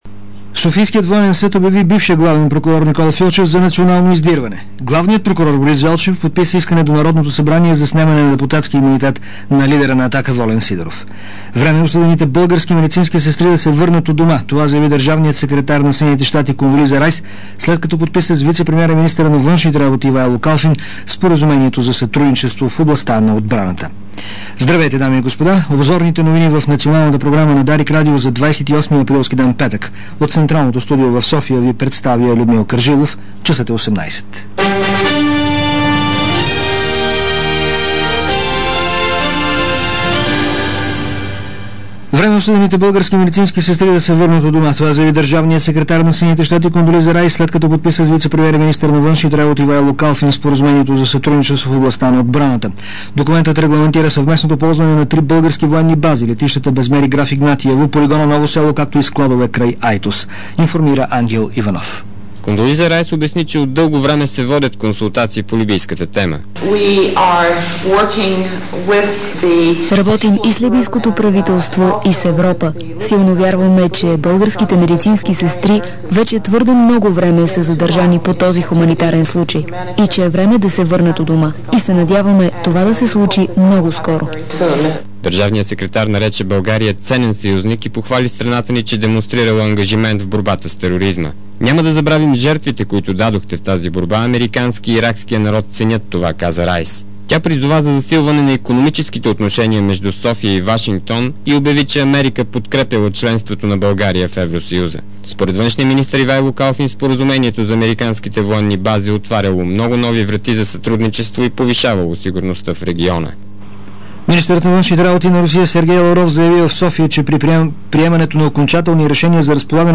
DarikNews audio: Обзорна информационна емисия 28.04.2006